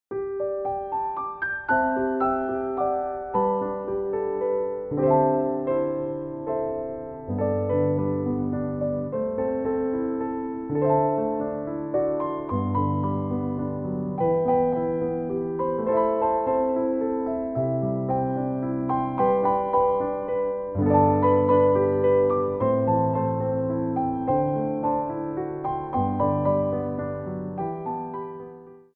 Ronds de Jambé en L'air
3/4 (8x8)